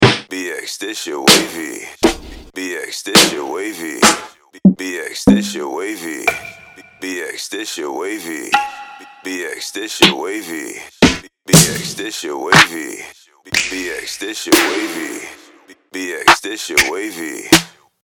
• Professionally Processed – Solid State Logic (SSL), Waves, Nomad Factory Mastering Plugins
BOOM BAP SNARES – 62
R&B SNARES – 34
HIP-HOP SNARES – 36
TRAP SNARES – 48